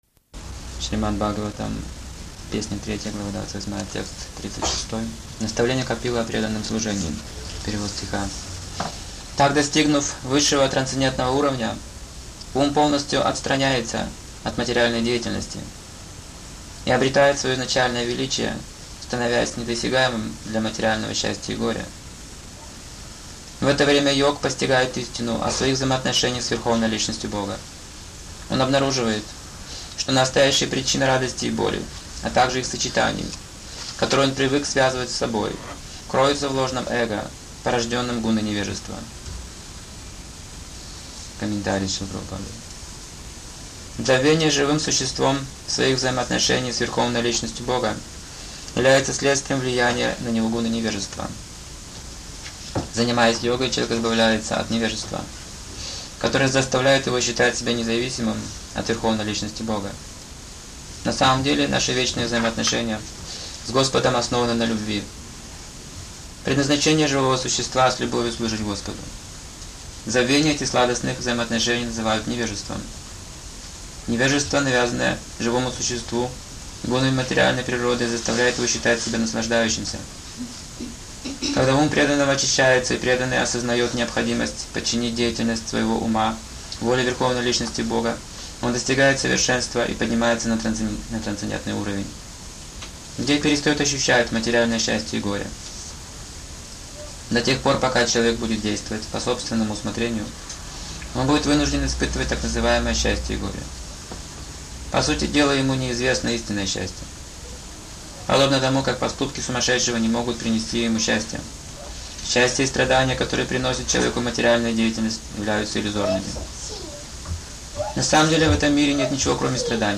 ШБ 3.28.36 (xx.01.1998, Витебск)